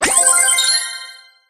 brawl_coinflip_01.ogg